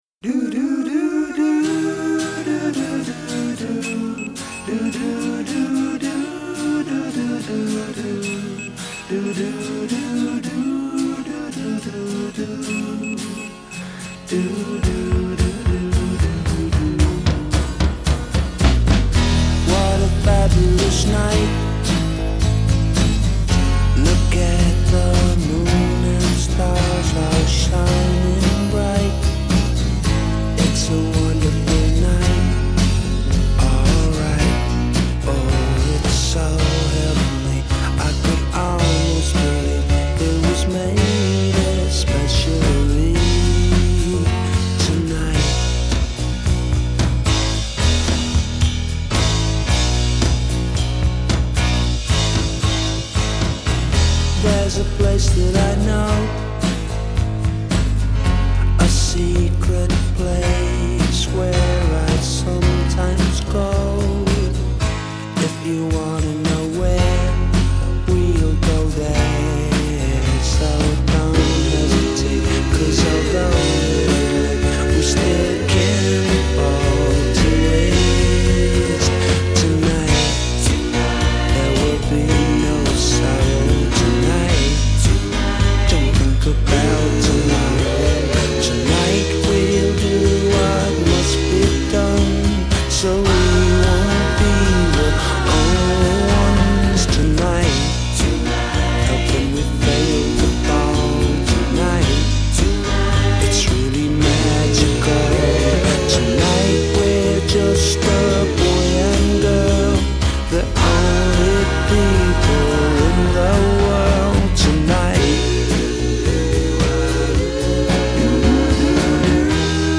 tuneful, ballady material